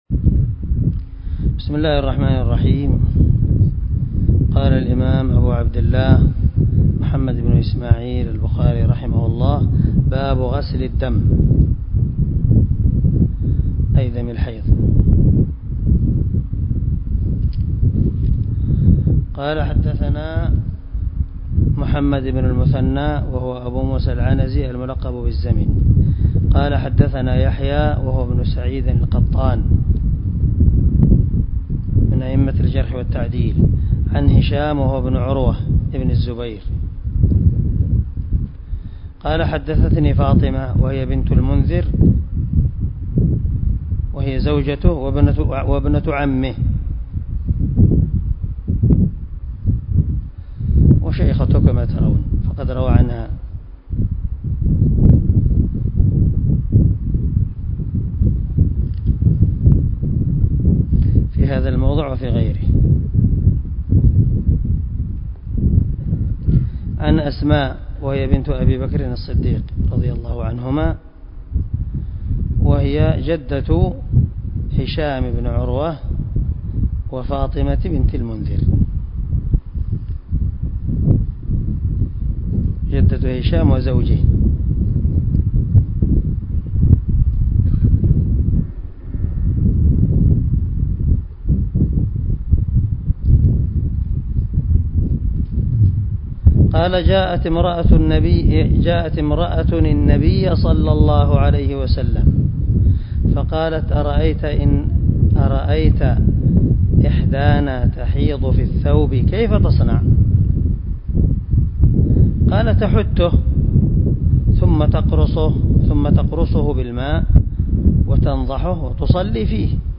191الدرس 67 من شرح كتاب الوضوء حديث رقم ( 227 ) من صحيح البخاري